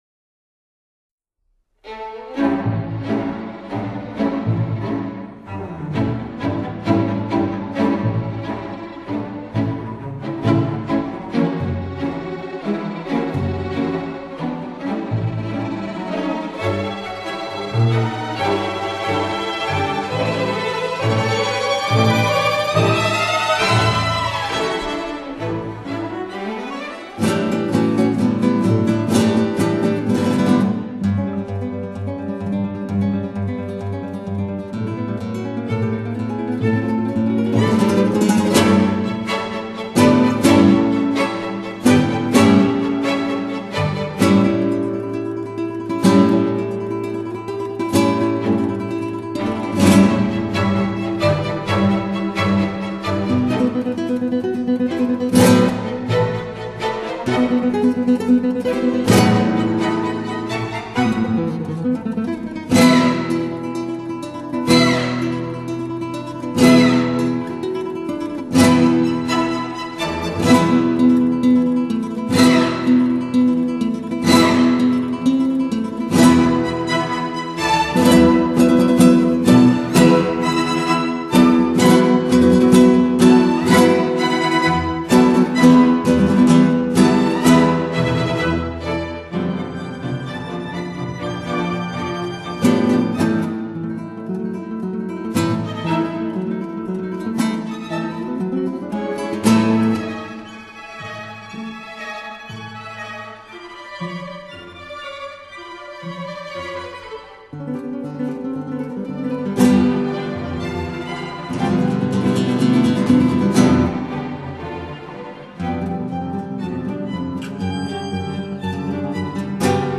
guitar
violin